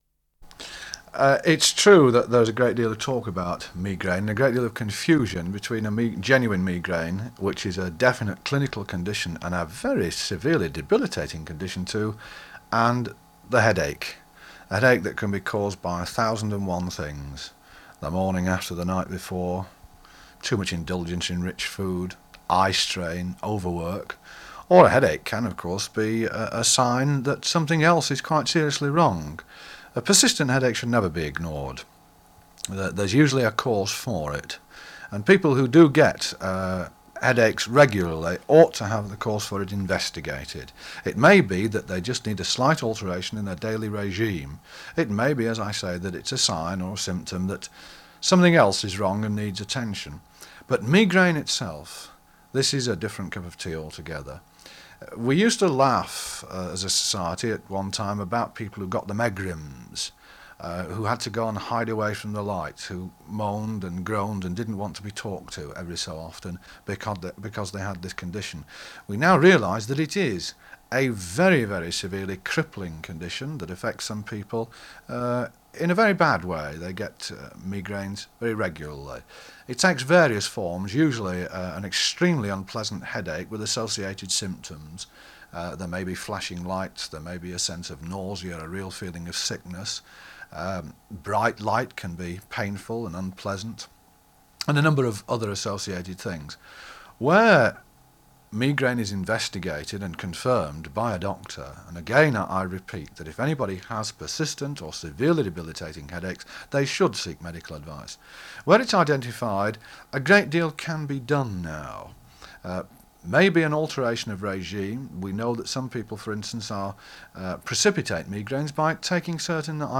Sheffield : BBC Radio Sheffield, 1972.